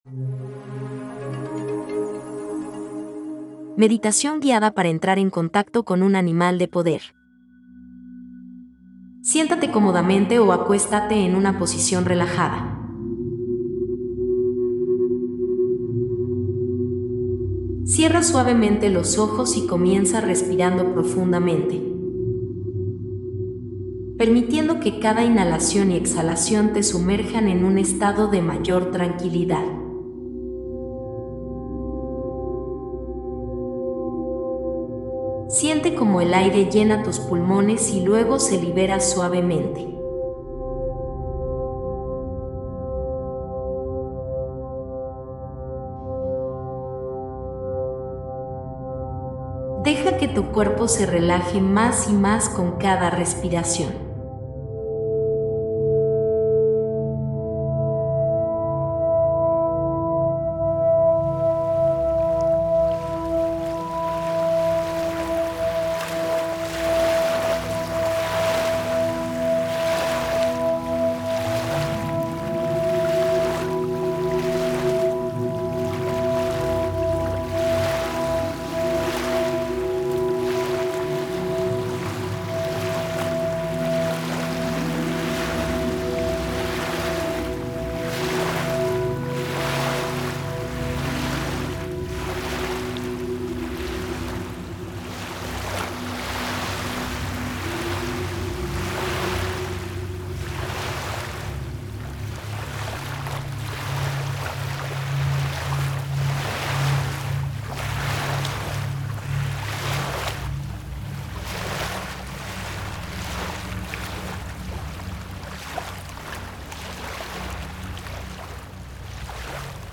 MEDITACIÓN GUIADA